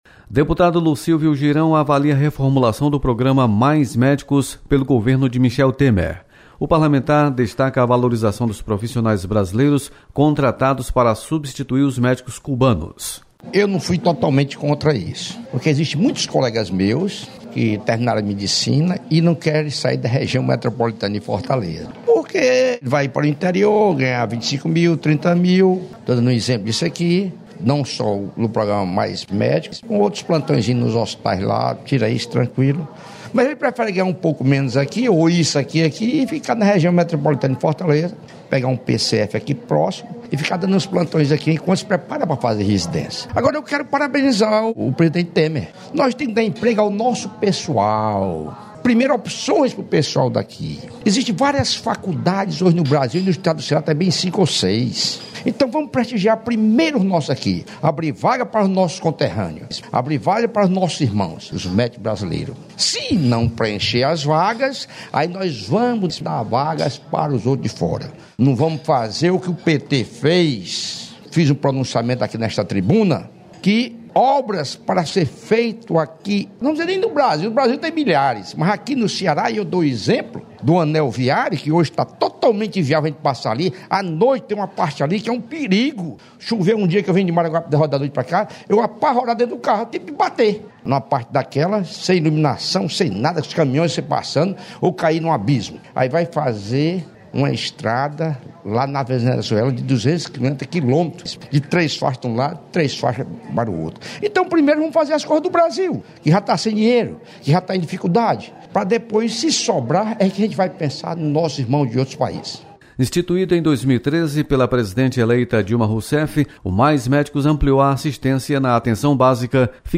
Deputado Lucílvio Girão destaca valorização de brasileiros no Programa Mais Médicos. Repórter